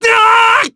Gladi-Vox_Damage_jp_03.wav